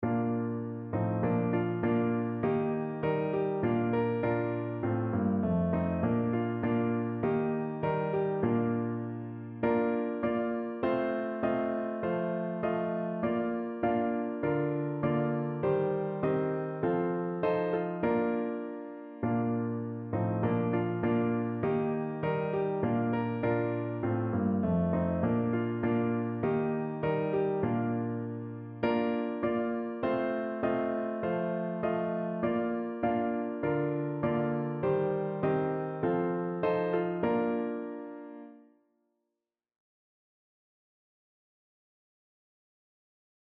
Hingabe Lob und Anbetung
Notensatz (4 Stimmen gemischt)